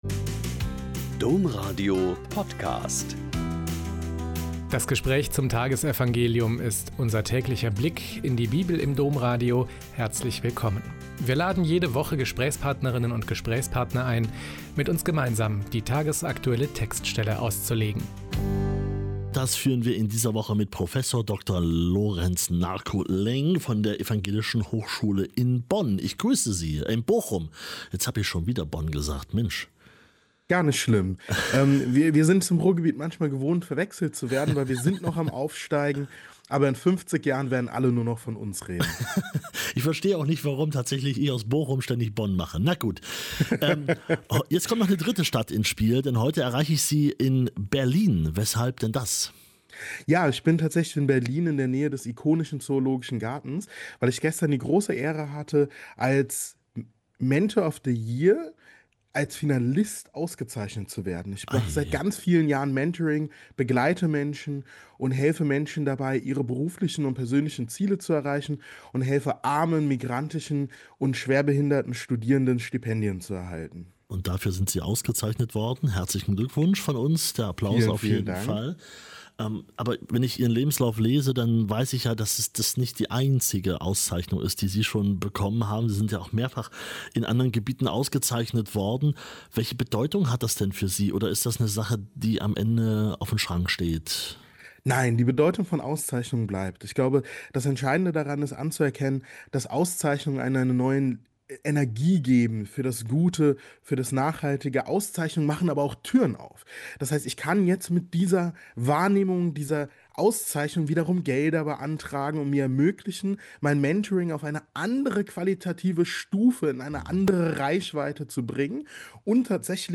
Lk 5,33-39 - Gespräch